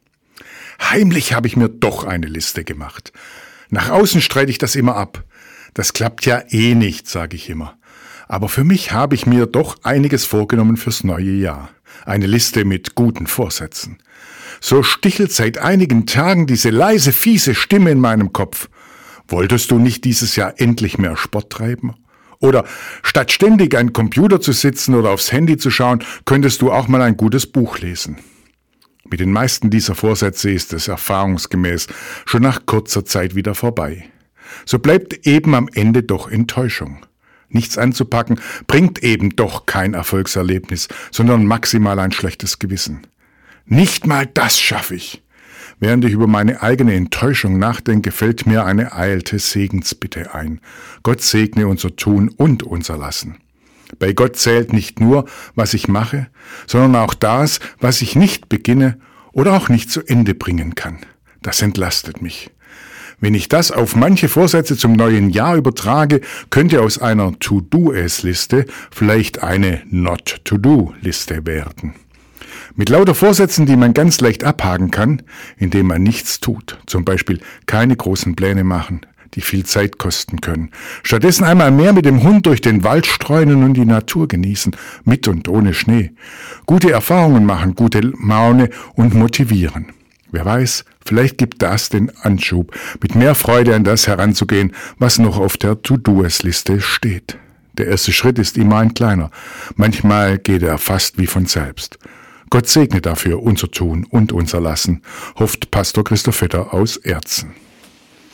Radioandacht vom 13. Januar